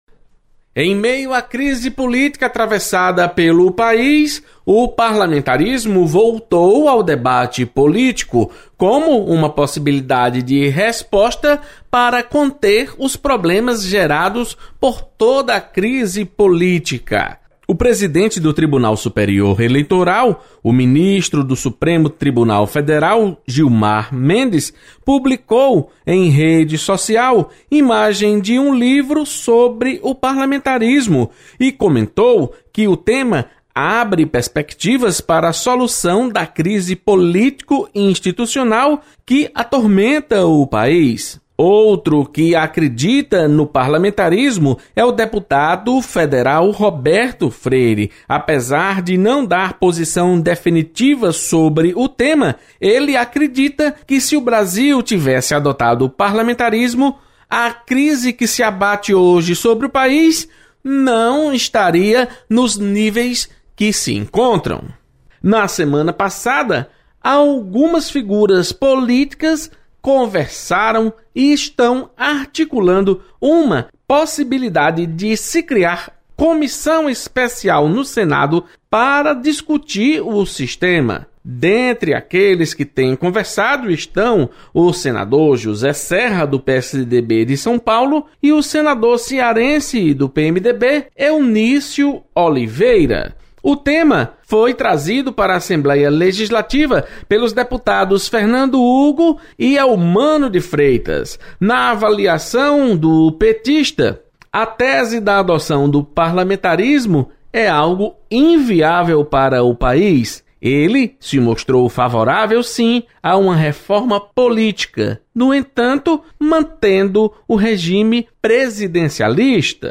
Deputados comentam sobre instalação de comissão para estudar adoção do parlamentarismo.